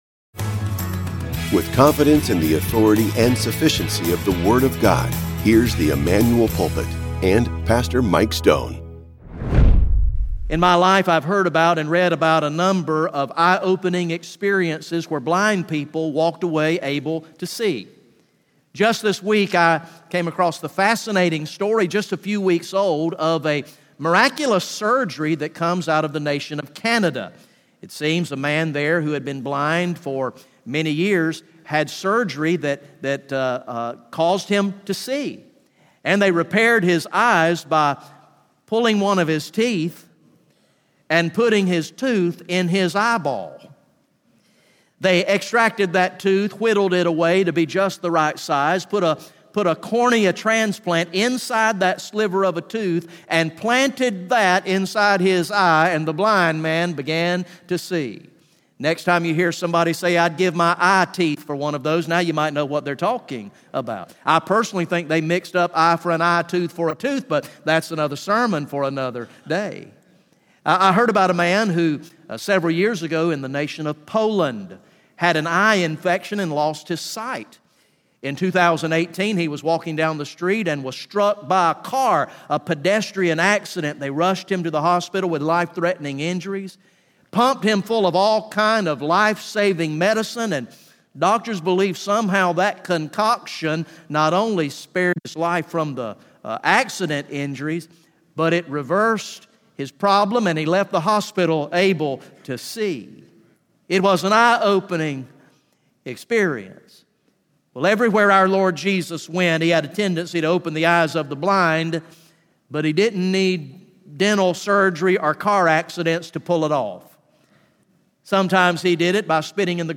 sermon
Sunday AM